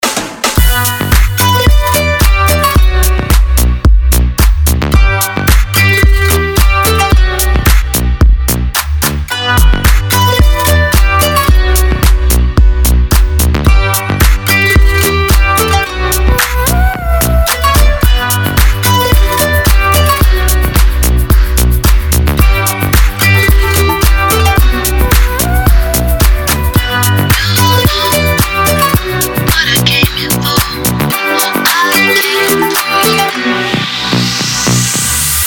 • Качество: 256, Stereo
громкие
мощные
deep house
восточные мотивы
атмосферные
женский голос
Electronic
арабские
Стиль: deep house